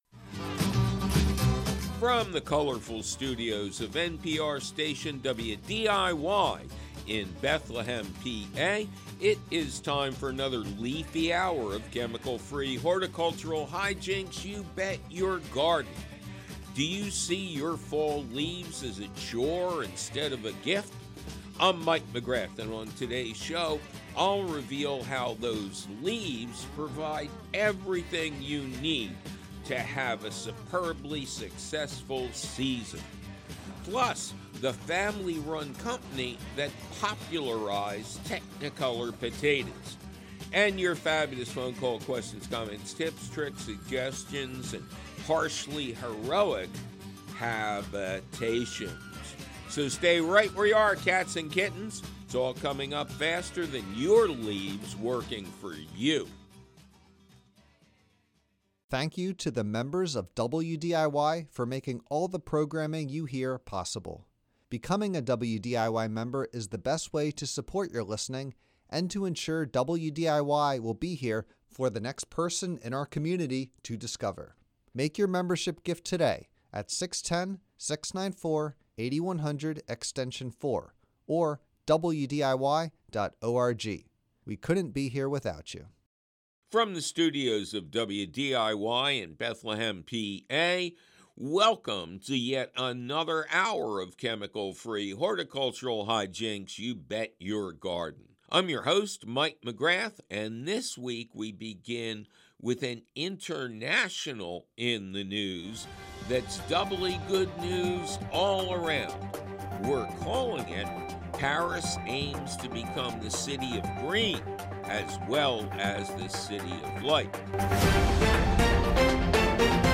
Plus, an interview with the owner of a farm revolutionizing potatoes, and your fabulous phone calls!